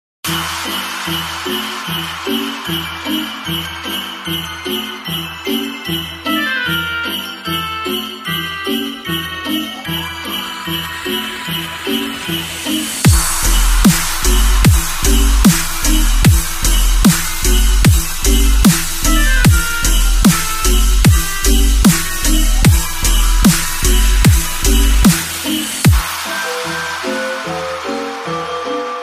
Kategorie Świąteczne